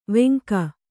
♪ veŋka